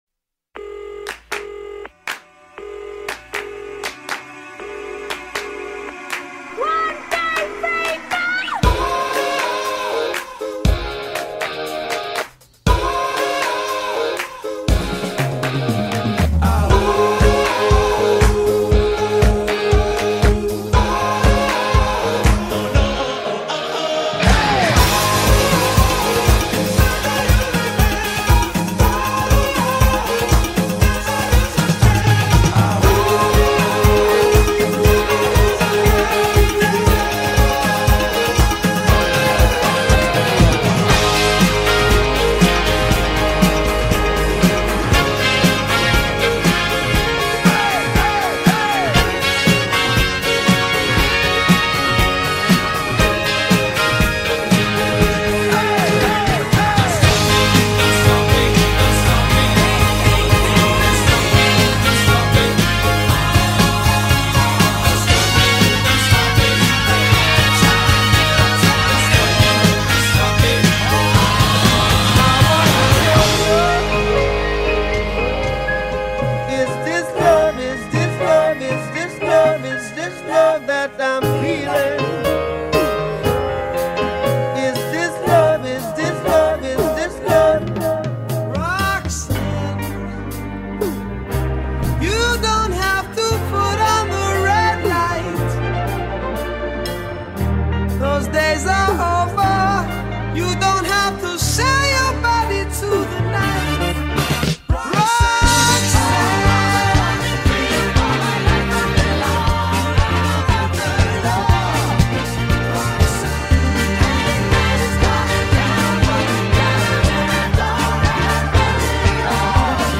70s music mix. 70s music mashup mix.